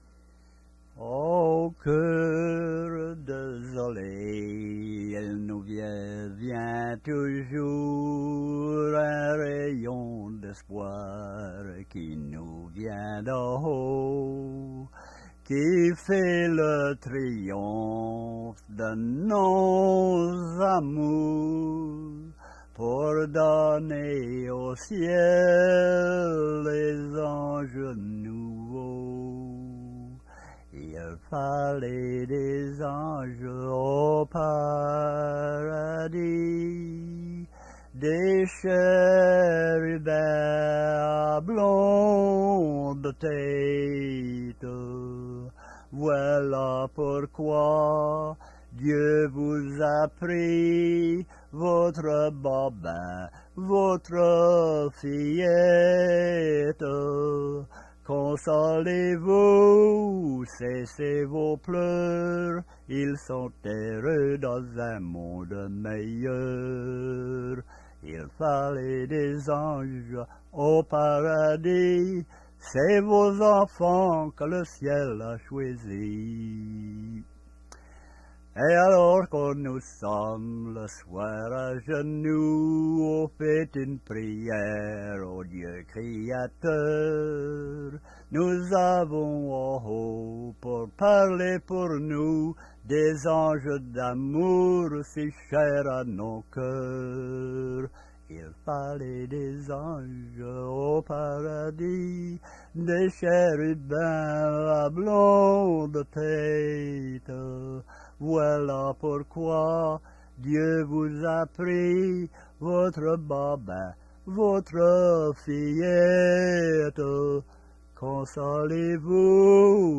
Chanson
Emplacement L'Anse-aux-Canards